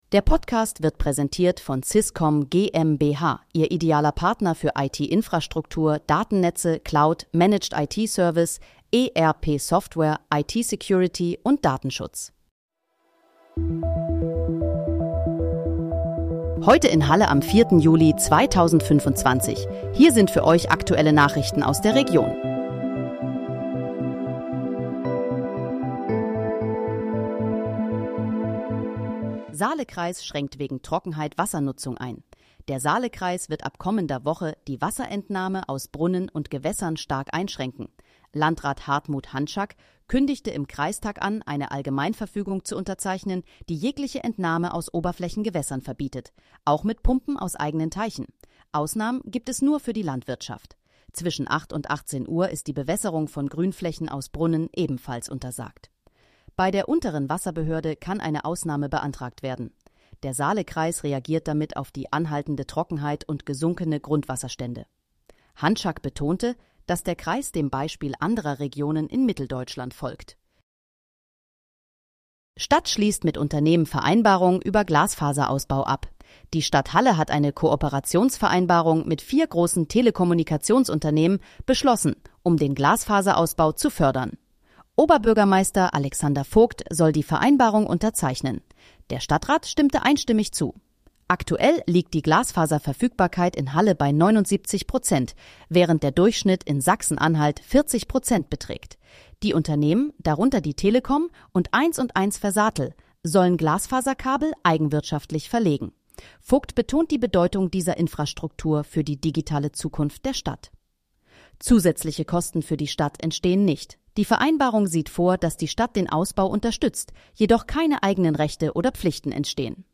Heute in, Halle: Aktuelle Nachrichten vom 04.07.2025, erstellt mit KI-Unterstützung
Nachrichten